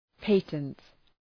patent Προφορά
{‘pætənt}